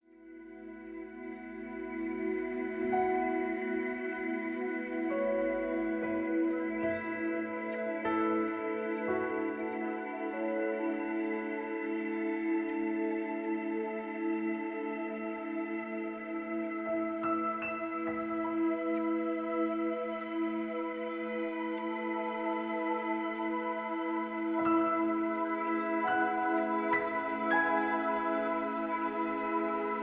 Ohne Sprache